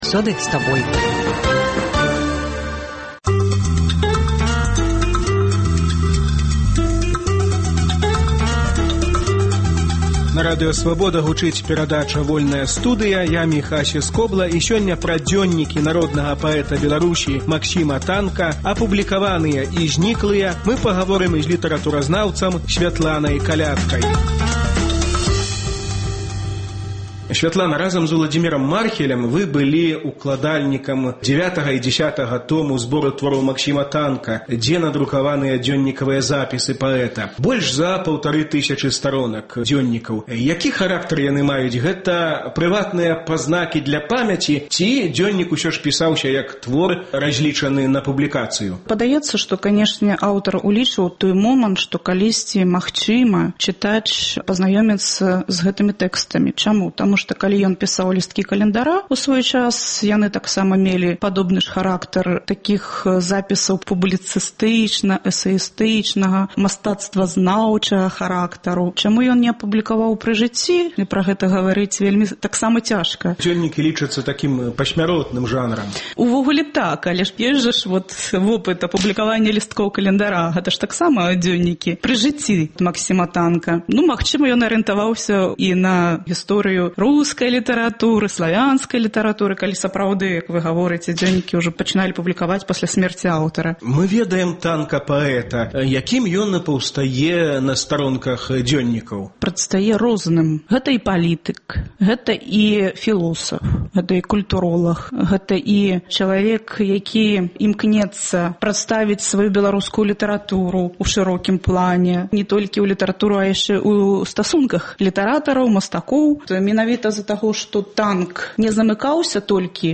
Гутаркі без цэнзуры зь дзеячамі культуры й навукі. Куды зьніклі дзёньнікі Максіма Танка?